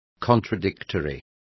Complete with pronunciation of the translation of contradictory.